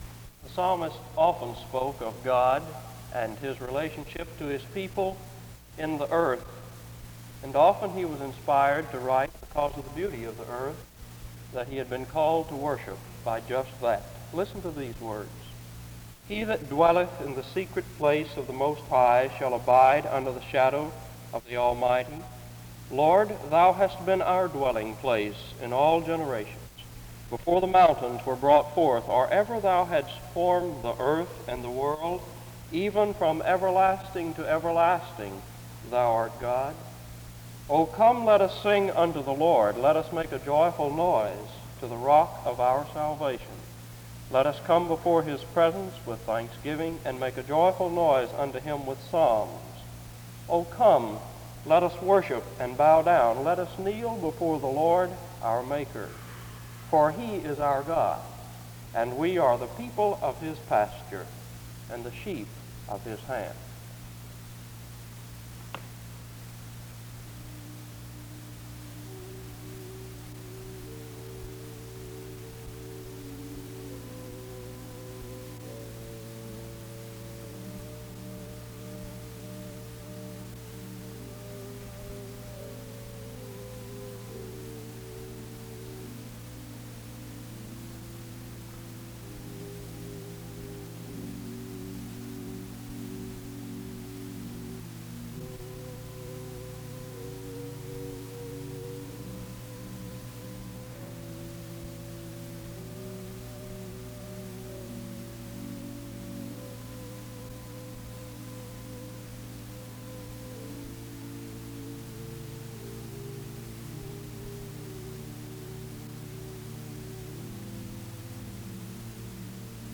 The service opens with a selective reading from 0:00-1:05. Music plays from 1:09-2:49. An introduction to the speaker is given from 3:08-5:42. Music plays from 5:43-8:06.
SEBTS Chapel and Special Event Recordings SEBTS Chapel and Special Event Recordings